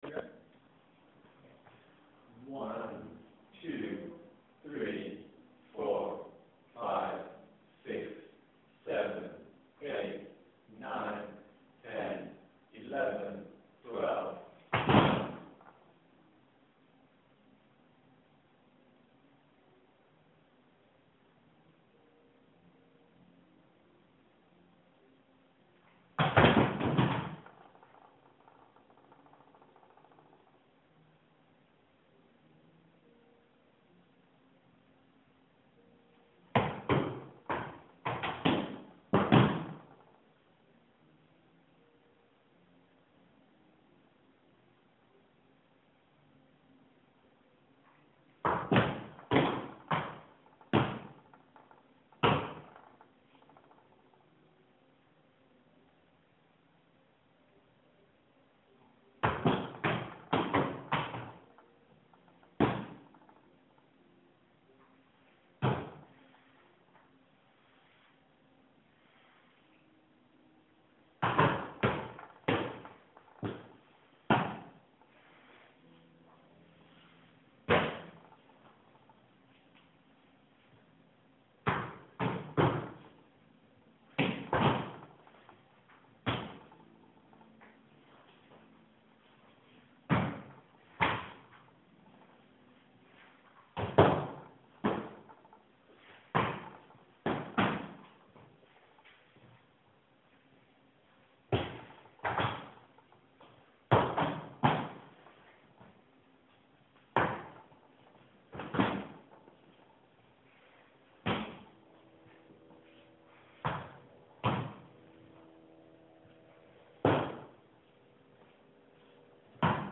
stamping_in_the_dark.wav